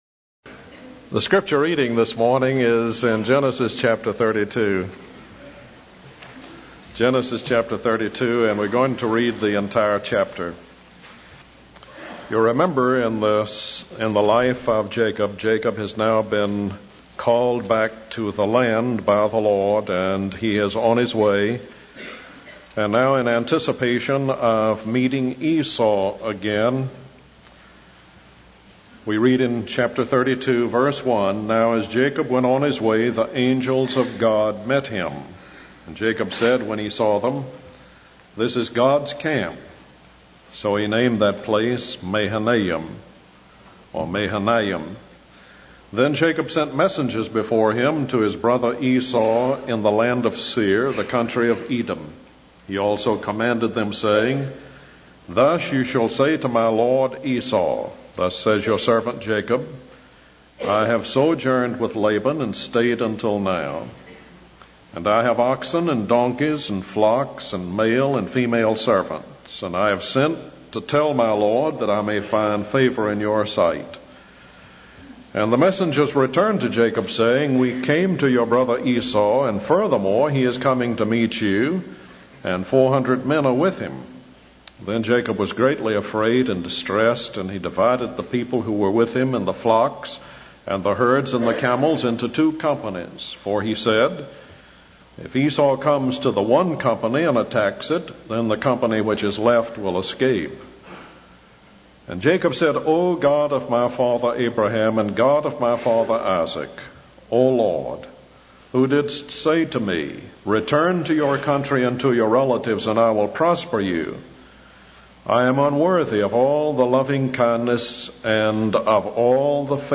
In this sermon, the preacher focuses on the life of Jacob and his journey from immaturity to maturity.